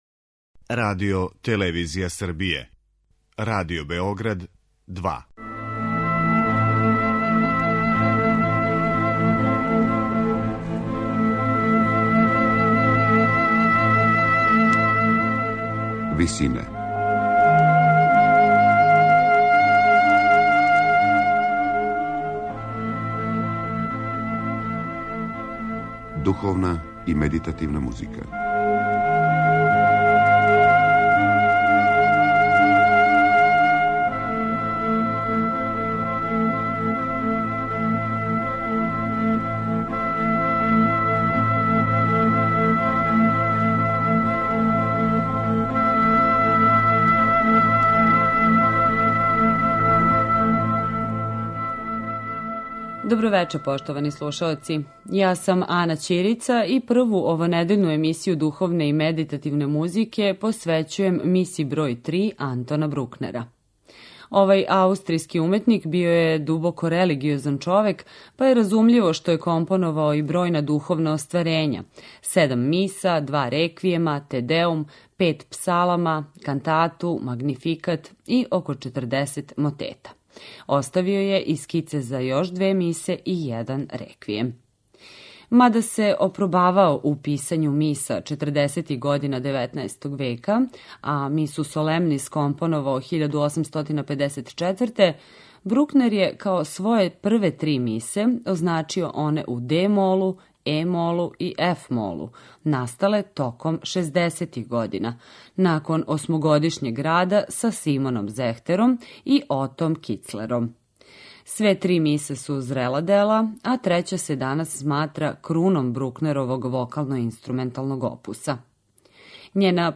Емисија је посвећена остварењу које се сматра круном Брукнеровог вокално-инструменталног опуса. Трећа Миса је компонована за солисте, четворогласни хор и оркестар, док су оргуље означене ad libitum.